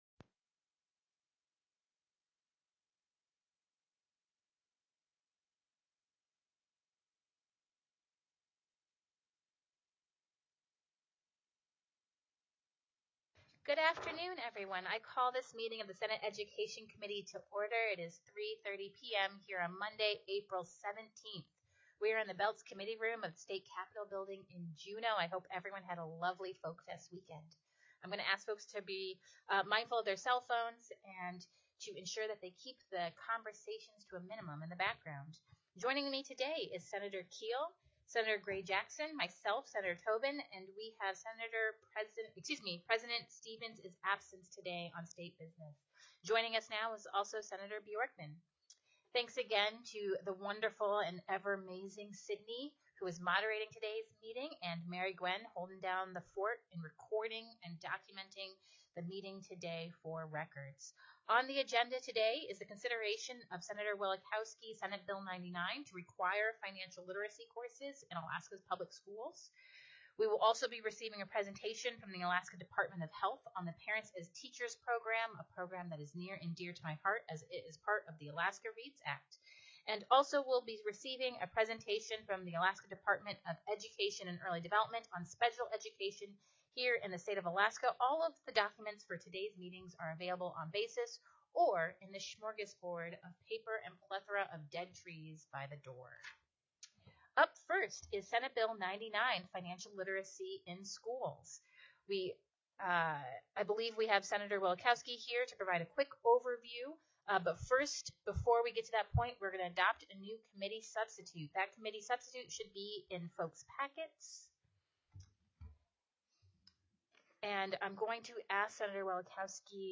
04/17/2023 03:30 PM Senate EDUCATION
The audio recordings are captured by our records offices as the official record of the meeting and will have more accurate timestamps.